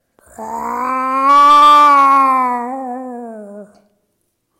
meows-6.mp3